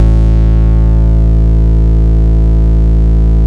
RAZOR BASS-L.wav